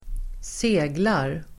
Uttal: [²s'e:glar]